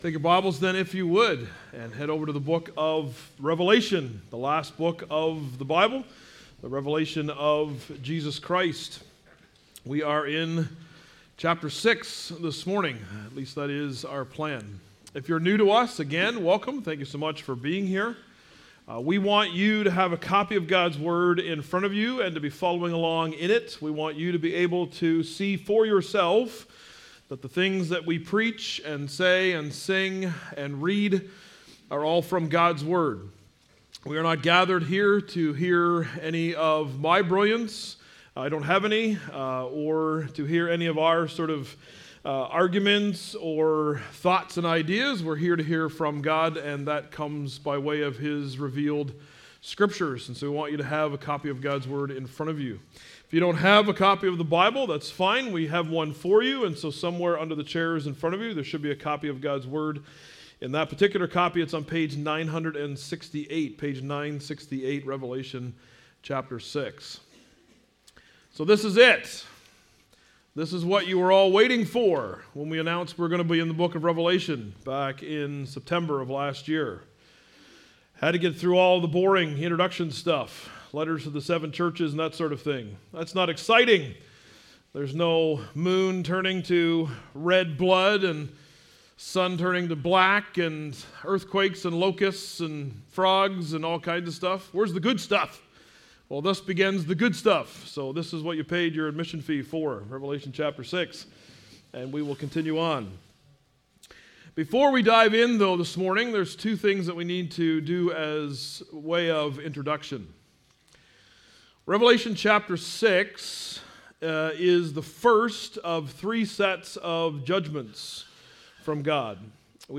Grace Sermon Podcast